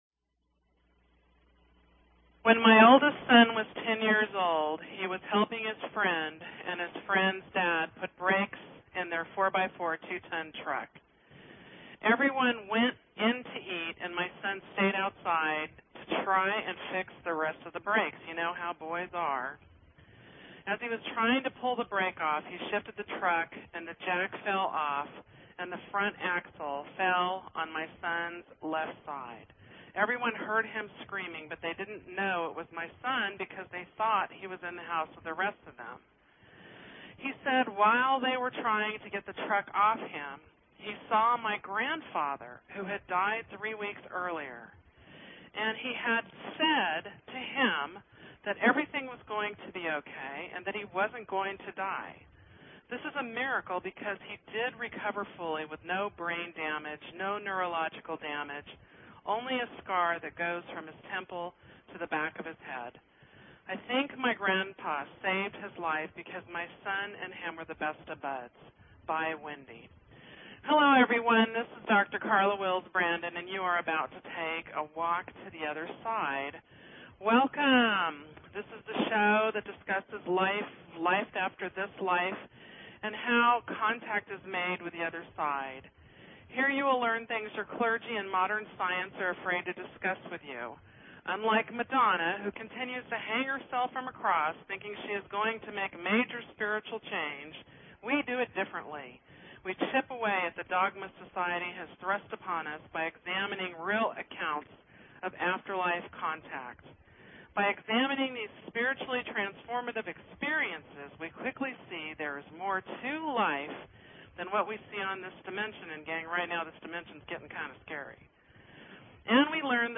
Talk Show Episode, Audio Podcast, A_Walk_To_Otherside and Courtesy of BBS Radio on , show guests , about , categorized as